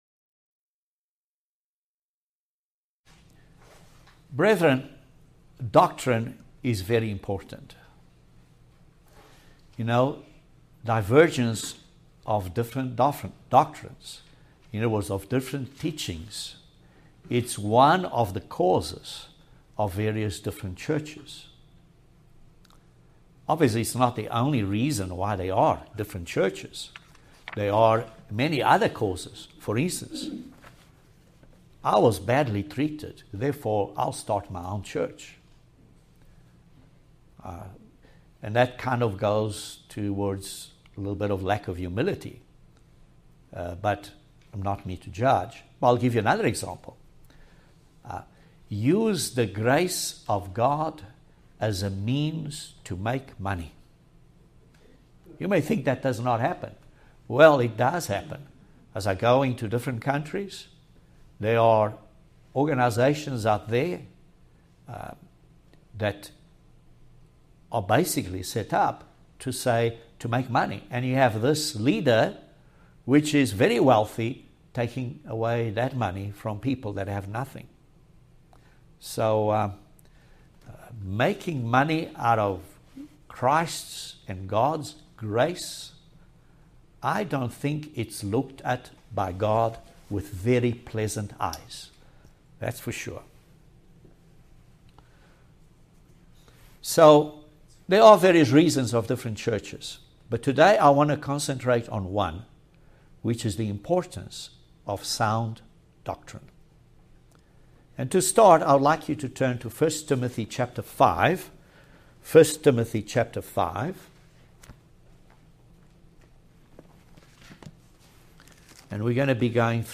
Join us for this very interesting Video sermon about the importance of sound doctrine.